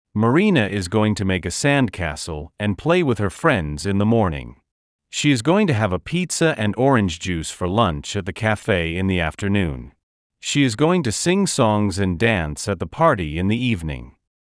[Марина из гоуинг ту мэйк э сэндкэсл энд плэй уиз хер фрэндс ин зе монинг. Ши из гоуинг ту хэва э пицца энд орандж джус фор ланч эт зе кафэ ин зе афтёрнун. Ши из гоуинг ту синг сонгс энд дэнс эт зе парти ин зе ивнинг.].